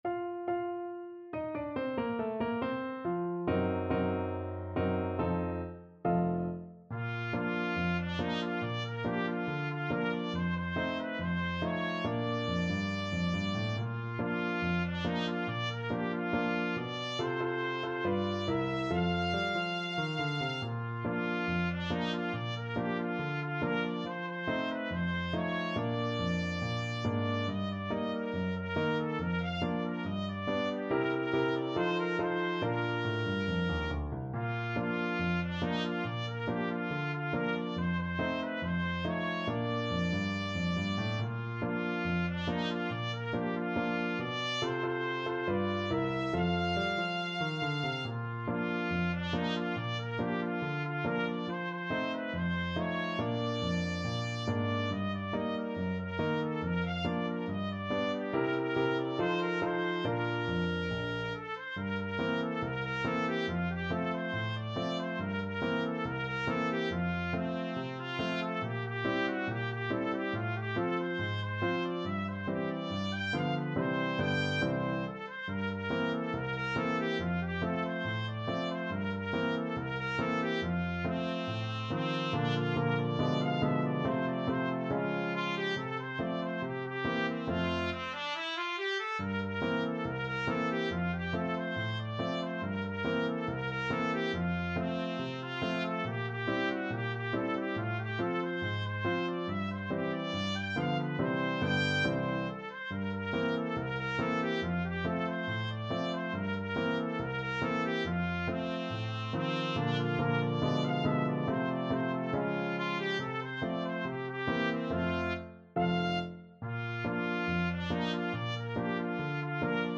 Trumpet
Bb major (Sounding Pitch) C major (Trumpet in Bb) (View more Bb major Music for Trumpet )
2/4 (View more 2/4 Music)
Not fast Not fast. = 70
Bb4-G6
Jazz (View more Jazz Trumpet Music)